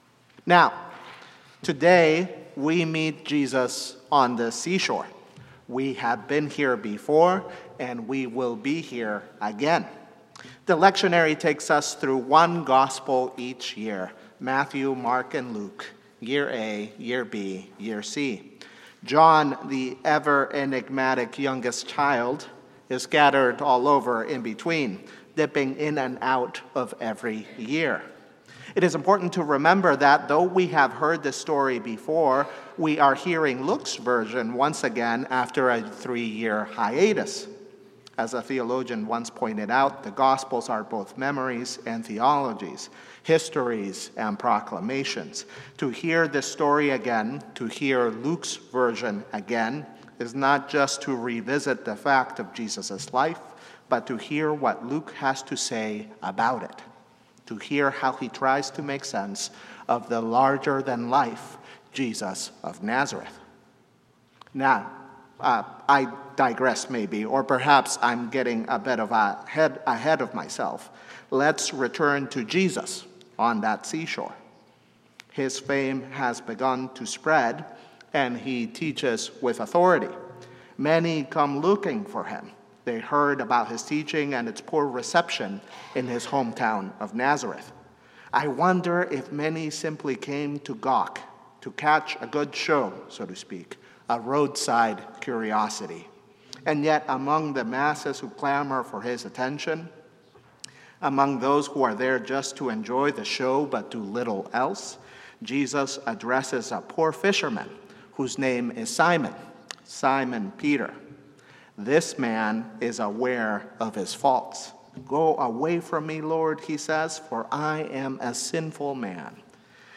St-Pauls-HEII-9a-Homily-09FEB25.mp3